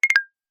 4. camera stopvideo